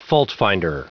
Prononciation du mot faultfinder en anglais (fichier audio)
faultfinder.wav